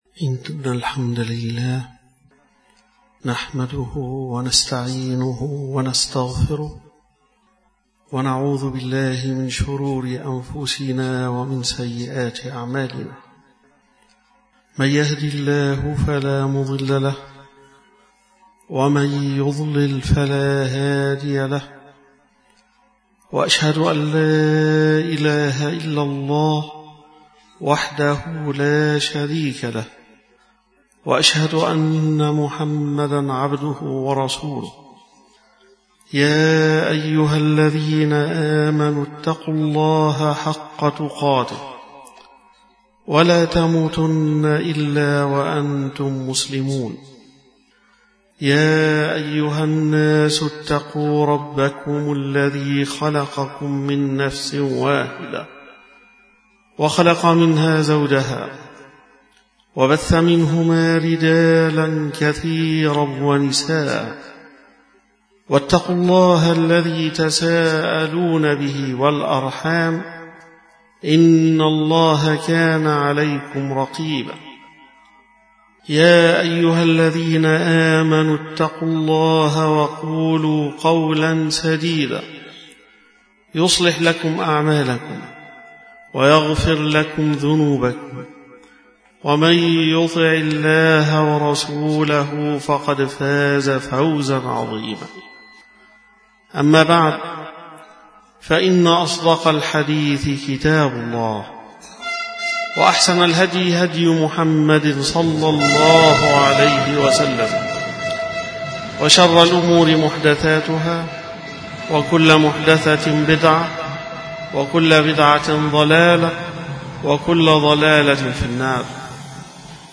ஐ مرئيات ஐ خطب الجمعة " تفسير سورة الأعراف "